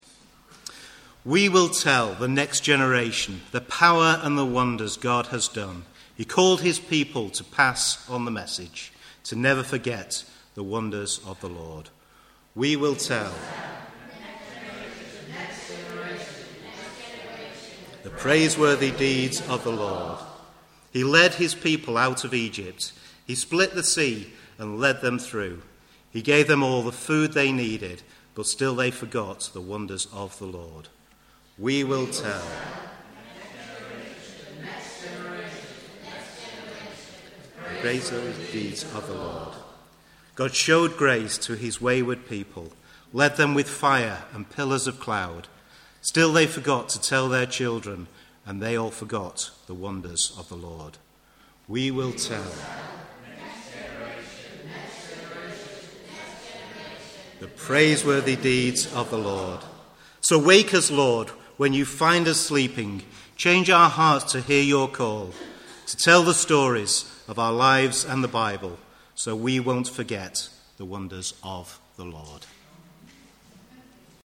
The service was led this week by the Explorers Group.  There is no recorded message - you can listen to the Praise Shout that was part of the service here